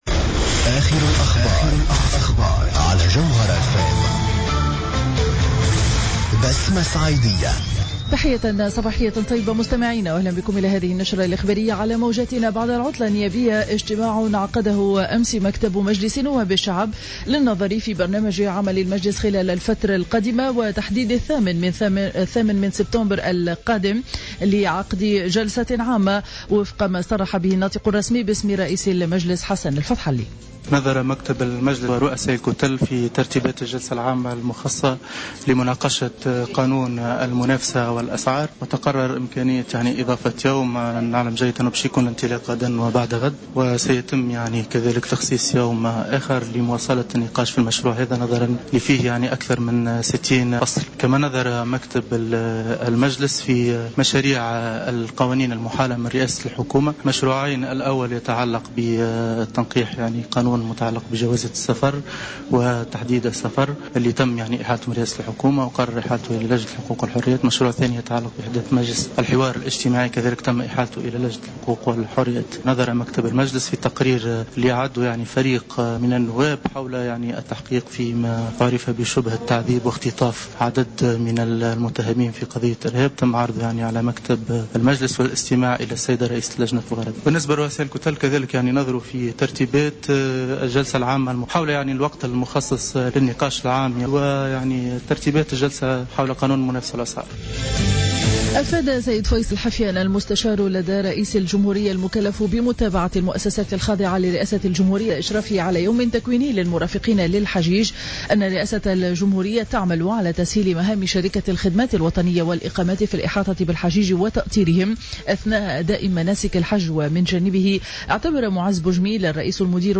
نشرة أخبار السابعة صباحا ليوم الخميس 27 أوت 2015